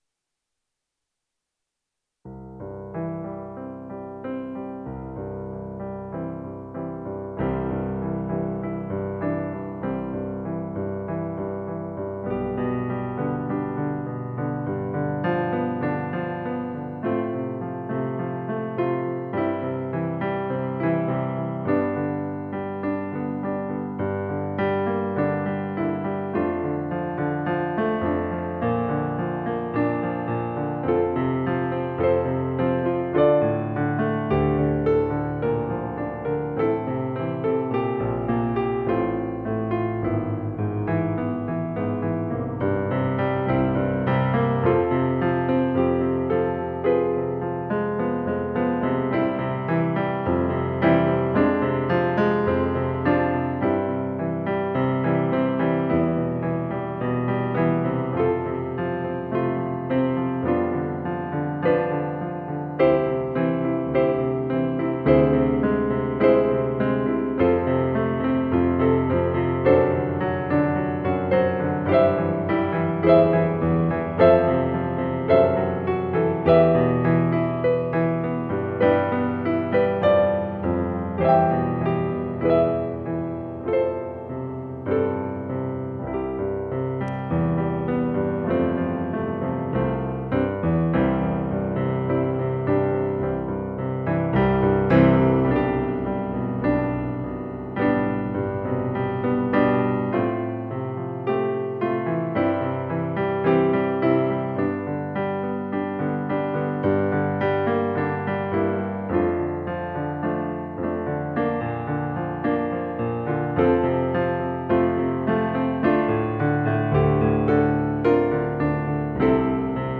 Sample audition accompaniment tracks: